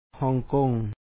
hòŋkoŋ Hong Kong